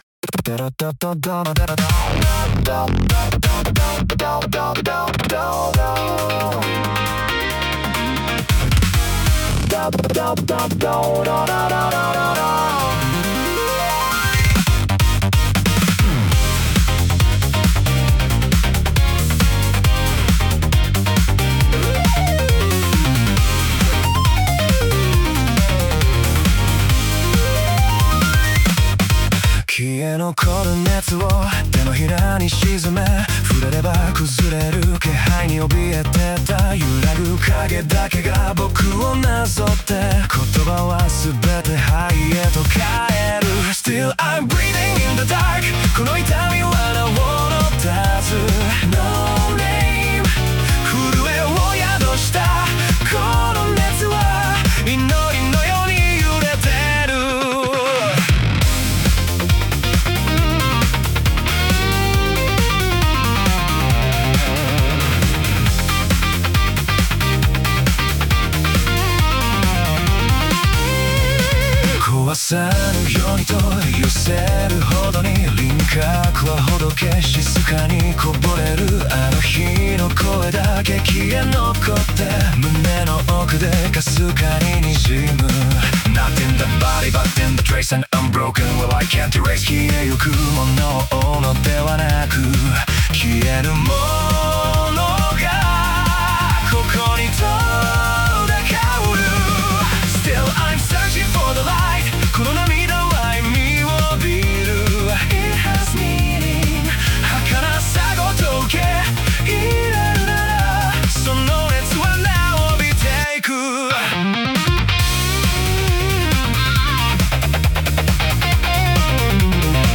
男性ボーカル